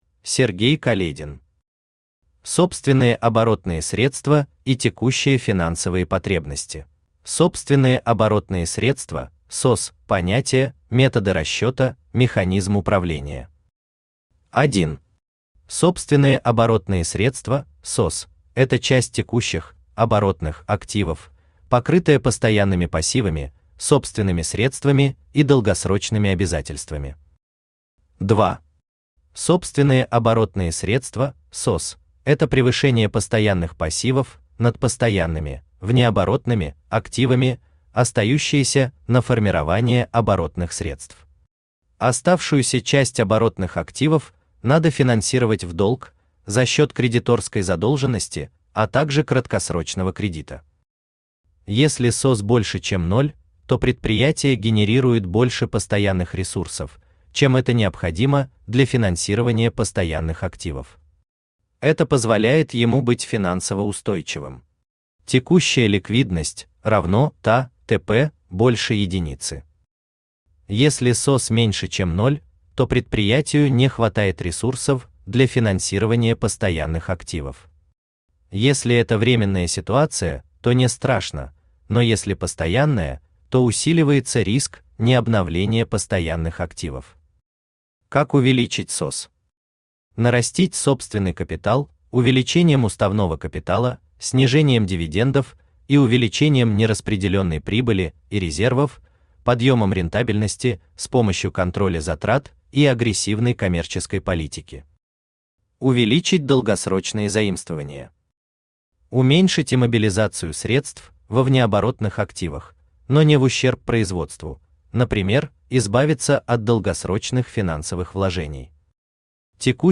Аудиокнига Собственные оборотные средства и текущие финансовые потребности | Библиотека аудиокниг
Aудиокнига Собственные оборотные средства и текущие финансовые потребности Автор Сергей Каледин Читает аудиокнигу Авточтец ЛитРес.